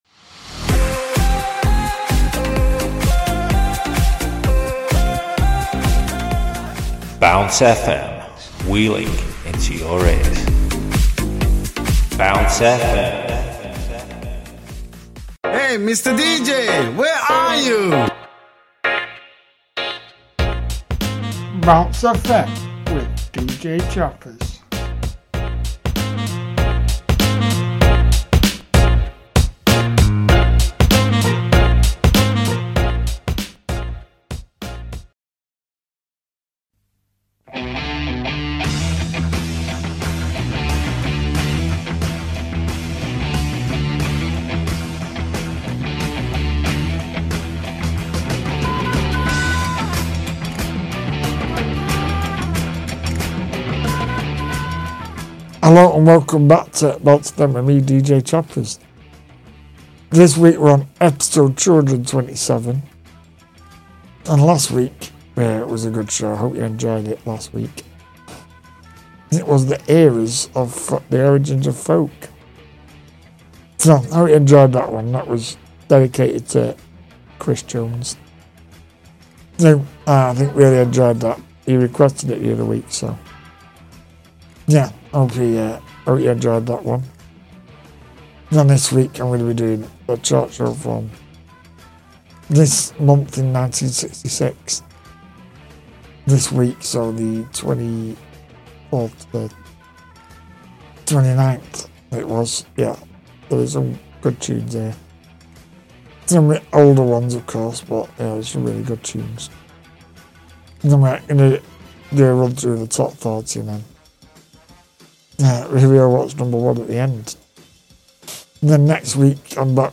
spinning and scratching some tracks